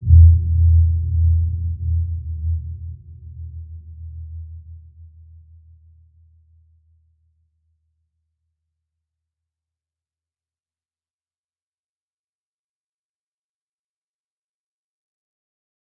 Dark-Soft-Impact-E2-p.wav